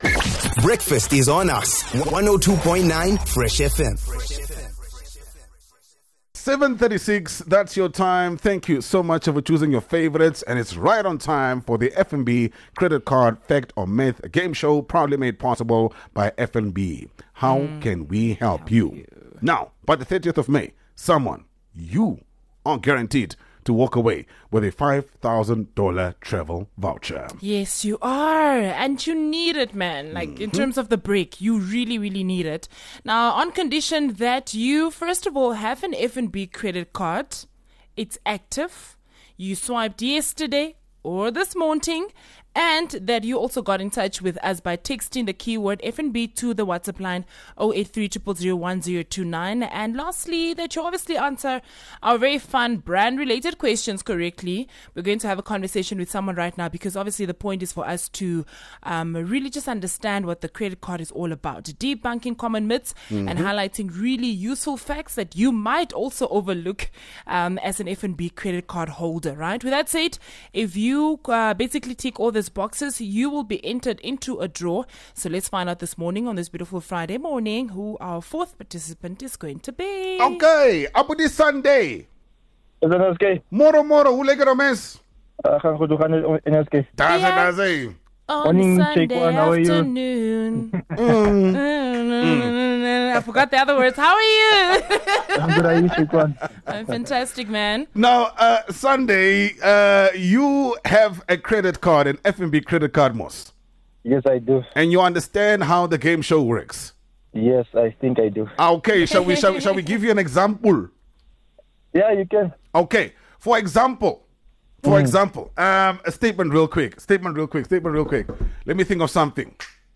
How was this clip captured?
Think you’ve got your credit card knowledge on lock? Fresh FM and FNB are here to put you to the test — with a fun, fast-paced segment that’s all about busting myths and rewarding facts! A game in which you could walk away with a N$5,000 travel voucher!